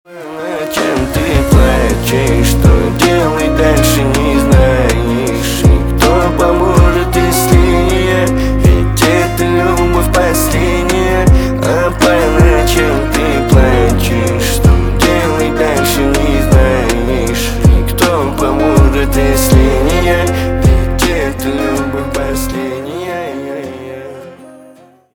на русском грустные про любовь